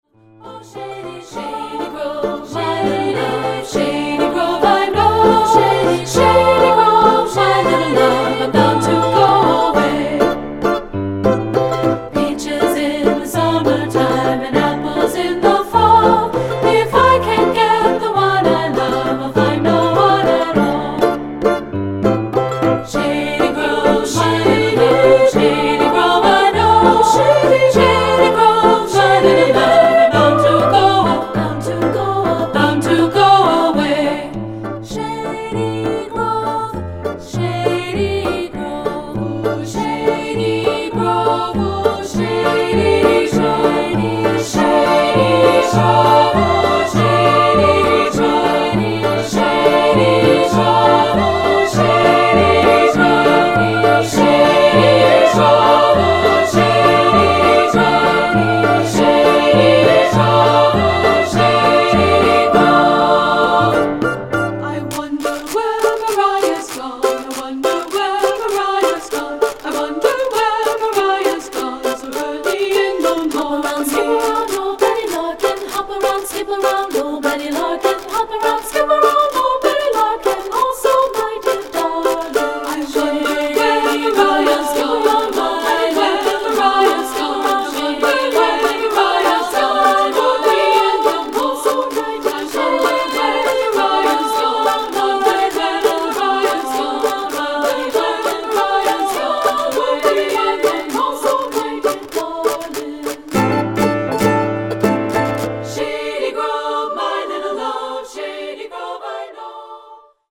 Choral Folk Women's Chorus
Appalachian Folk Song
SSA Divisi